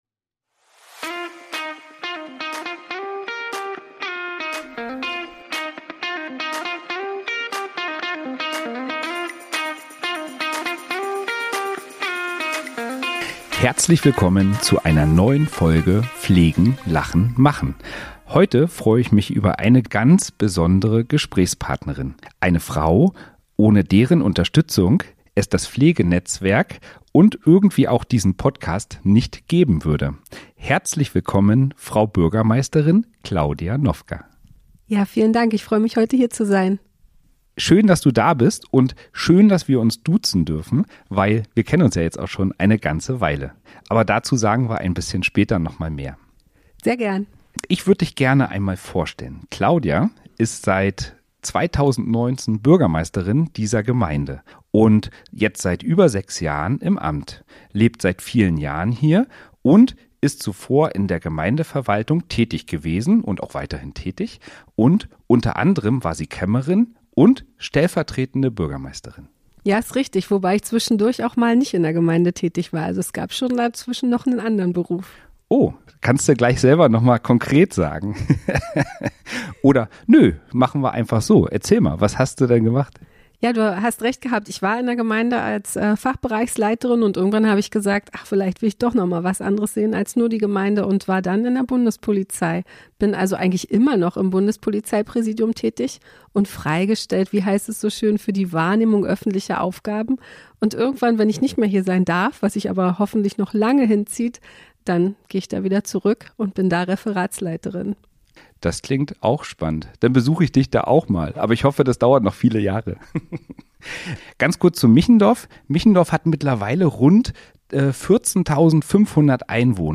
In dieser Folge von „Pflegen.Lachen.Machen.“ ist die Bürgermeisterin der Gemeinde Michendorf, Claudia Nowka, zu Gast.